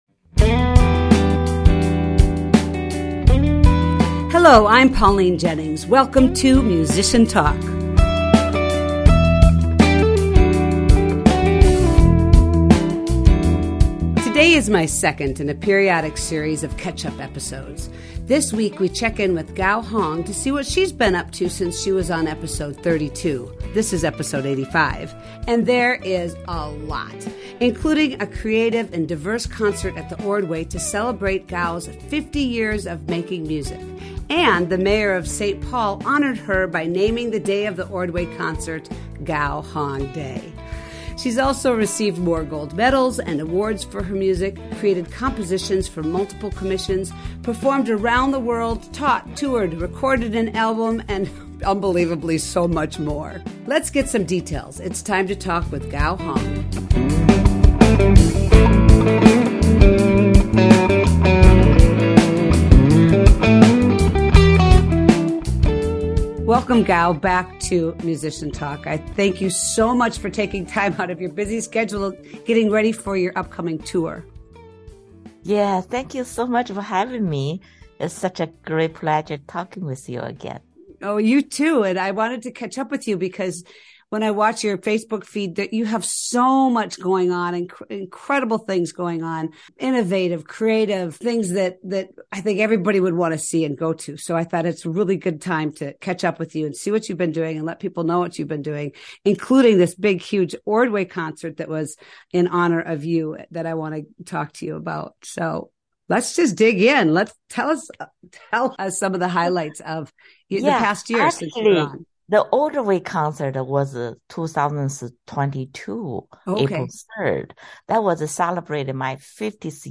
catches up with Gao Hong, professional Chinese Pipa Player.